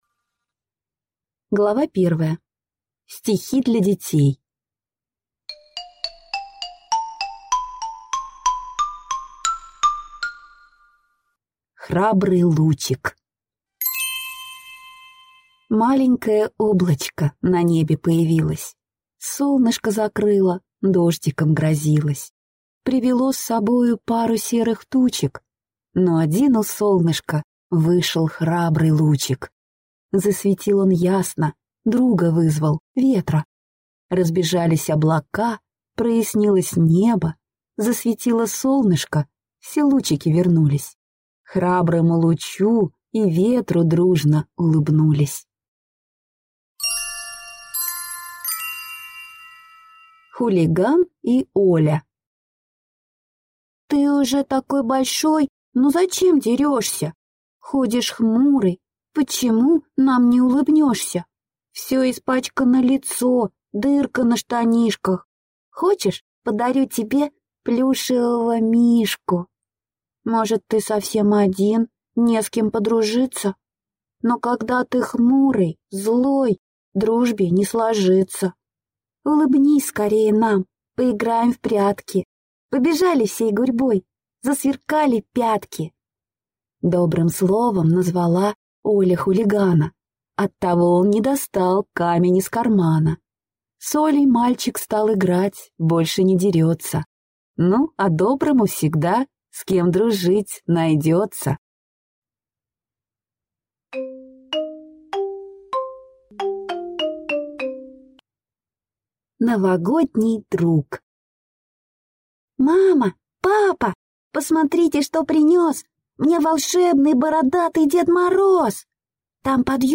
Аудиокнига В волшебной стране. Сборник стихов и сказок | Библиотека аудиокниг